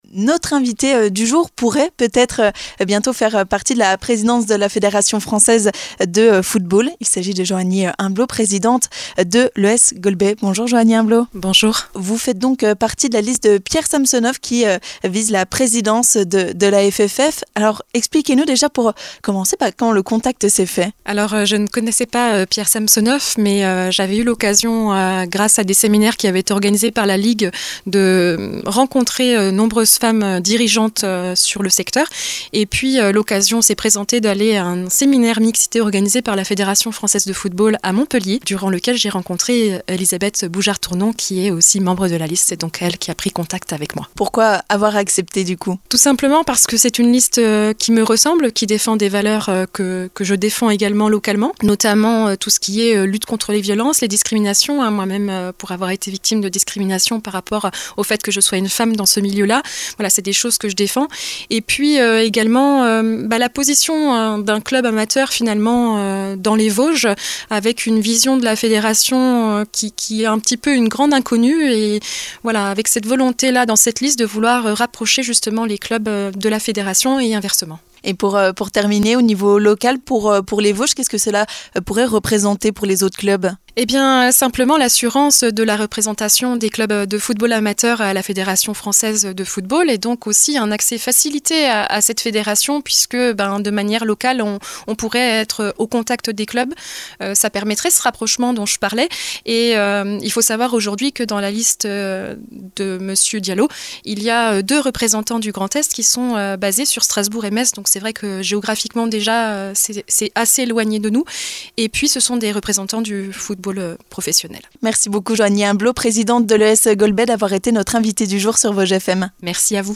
3. L'invité du jour